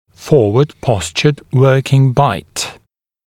[‘fɔːwəd ‘pɔsʧəd ‘wɜːkɪŋ baɪt][‘фо:уэд ‘посчэд ‘уё:кин байт]сдвинутый вперед рабочий прикус (о функц. аппаратах)